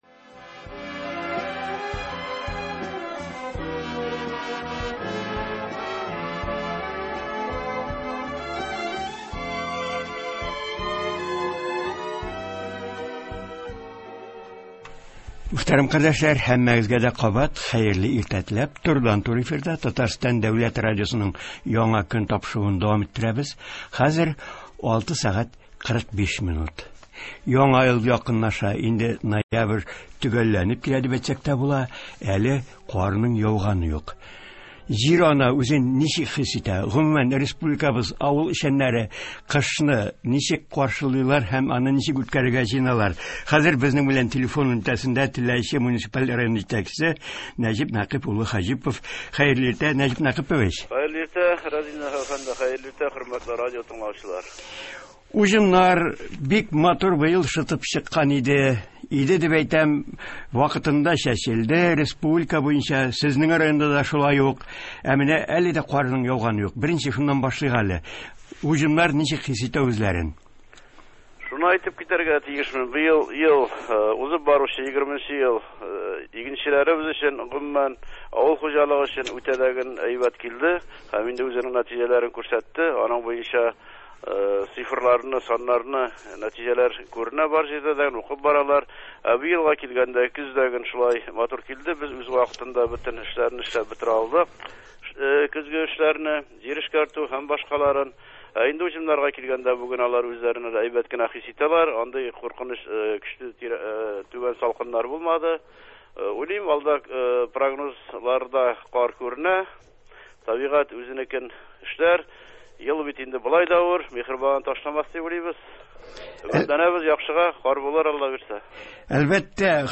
Теләче районында бу эшләр ничек башкарыла? Муниципаль район җитәкчесе Нәҗип Нәкыйп улы Хаҗипов телефон элемтәсендә шул хакта сөйли.